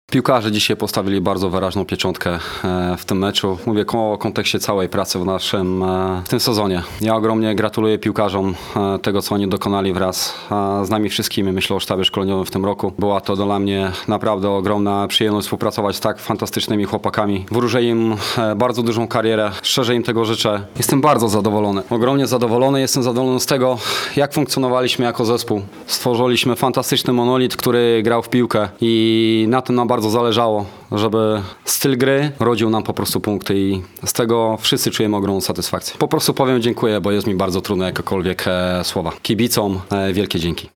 powiedział na pomeczowej konferencji